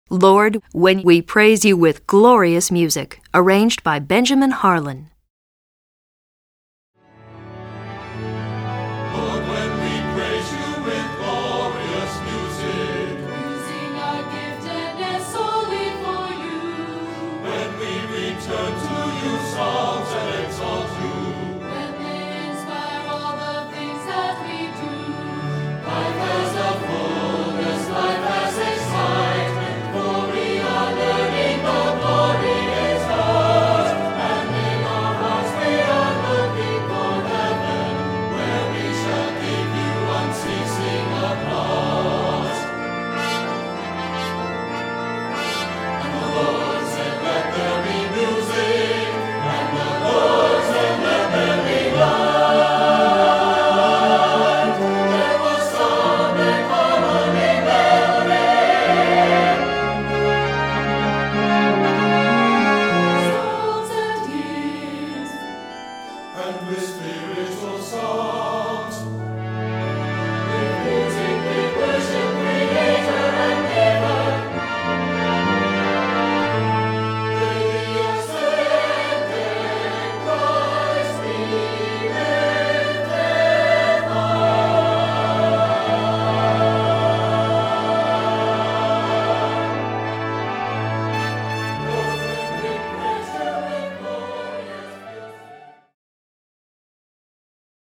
Choral Church
The words are elegant and his melody joyful.
SATB